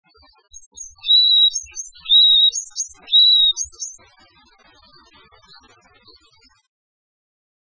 2126e「鳥の鳴声」
〔ヤマガラ〕ニーニーニーピーピーピー／ツーツーピーン，ツーツーピーン（さえずり
yamagara.mp3